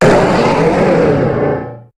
Cri de Méga-Drattak dans Pokémon HOME.
Cri_0373_Méga_HOME.ogg